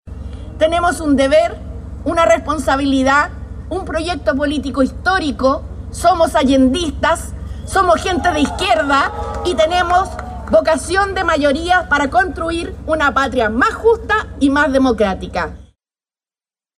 La inscripción se concretó el pasado miércoles, en el Servicio Electoral (Servel), donde se realizó un acto cuyo objetivo principal fue dar una señal de unidad en el sector.